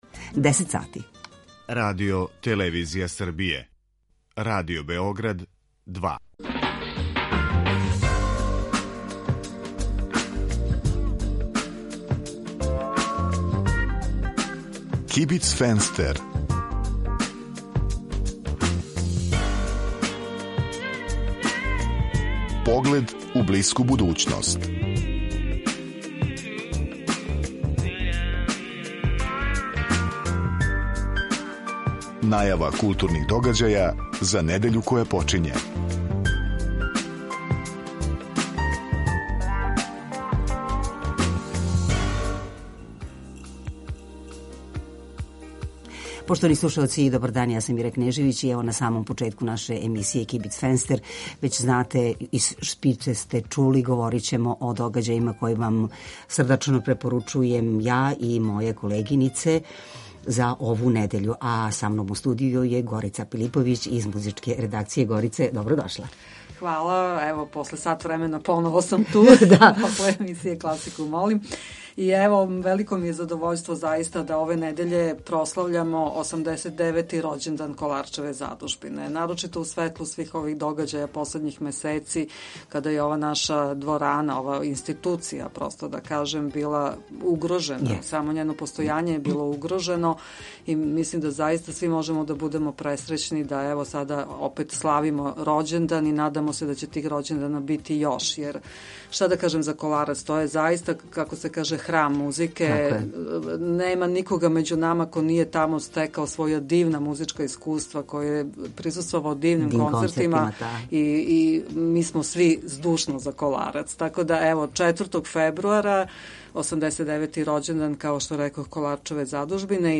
У некој врсти културног информатора за недељу која је почела, чућете аргументован избор и препоруку новинара и уредника РБ 2 из догађаја у култури који су у понуди у тој недељи. Свака емисија има и госта (госте), чији избор диктира актуелност – то је неко ко нешто ради у тој недељи или је везан за неки пројекат који је у току.